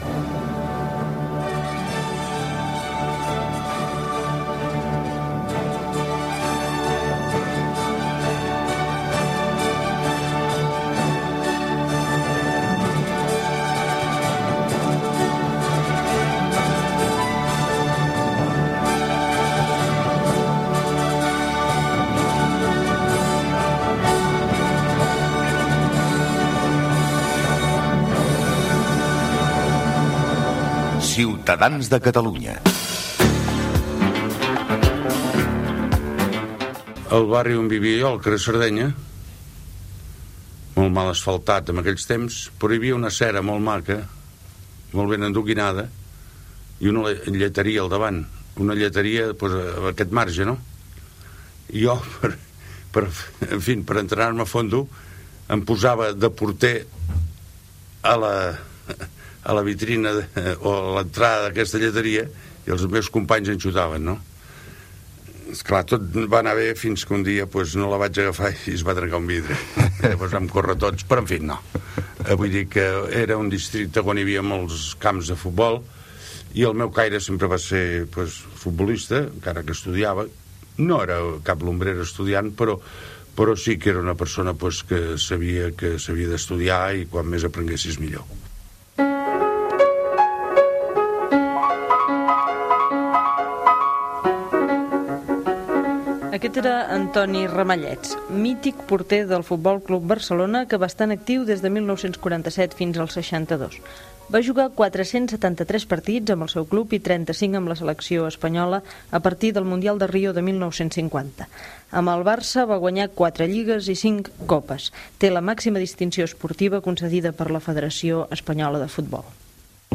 Careta i espai dedicat al porter de futbol Antoni Ramallets